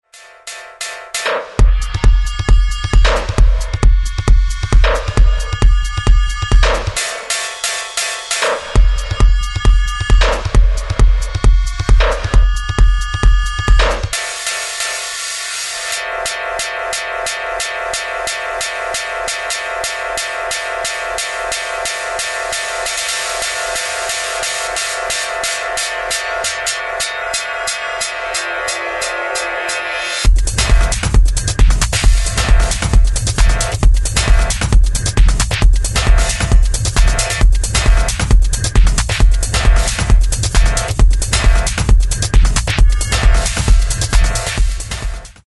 Straight up banging techno